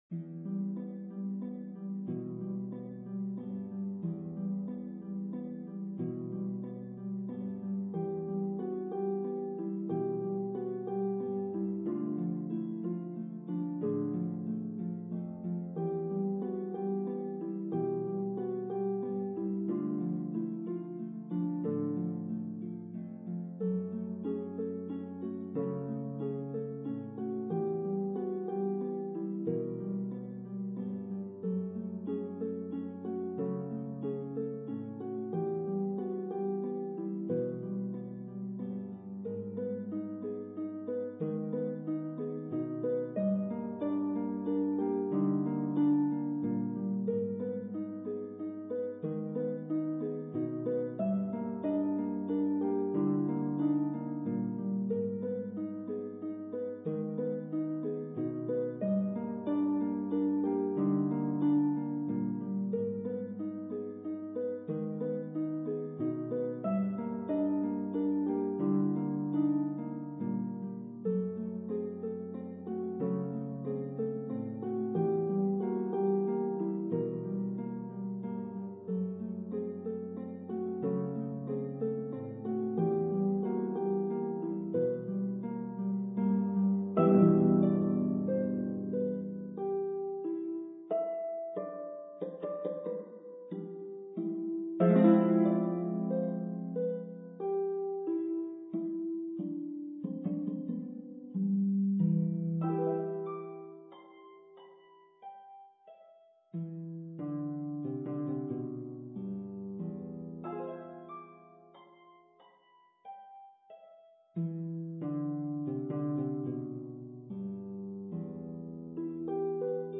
two harp version
Harp Duo